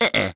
Neraxis-Vox-Deny.wav